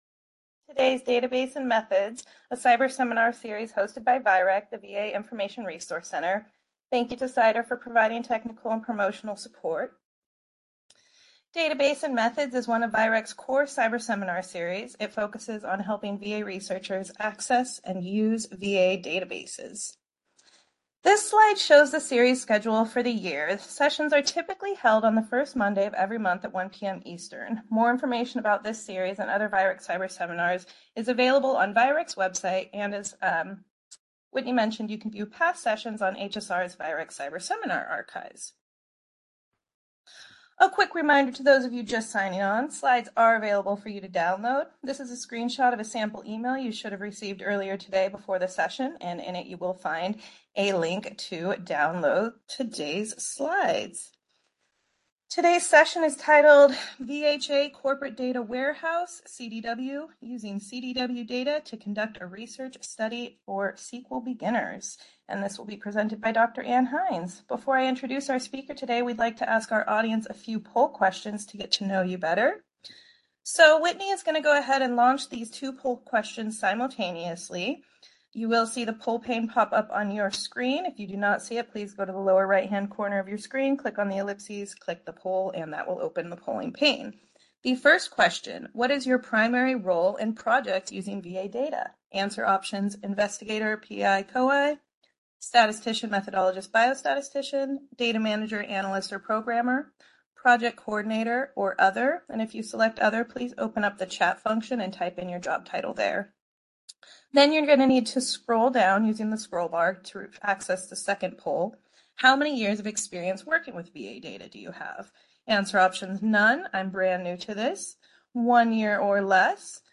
VIReC Database and Methods Seminar